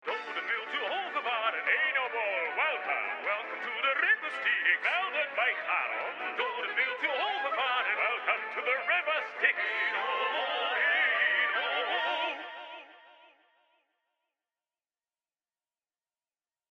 De locatie was een oude verensmederij van de NS in Amersfoort.
De muziek was van—inmiddels ook bij mij—bekende Nederlander Chiel Meijering, en werd uitgevoerd door orkest De Volharding.
Ter illustratie een kort fragmentje van de tune die Styx-schipper Charon opzette bij elke nieuwe dode: